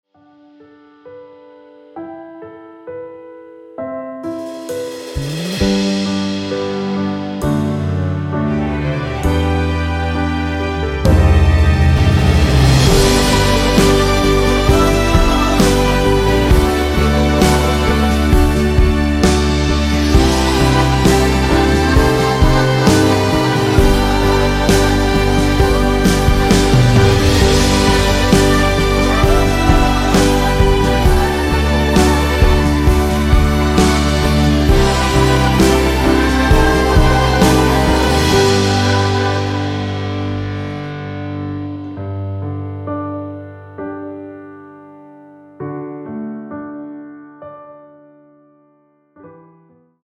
미리듣기에 나오는 부분이 코러스로 추가되었습니다.
원키 코러스 포함된 MR입니다.
미리듣기에선 첫 출발부터 웅장하길래